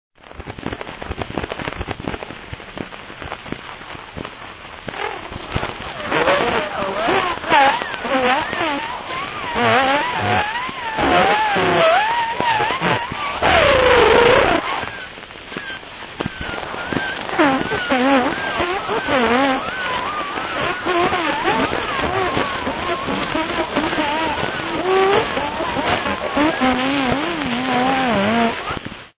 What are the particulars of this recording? The world earliest playable phonograph recording – voices from 1878 of an experimental talking clock. To hear the fourth section reversed —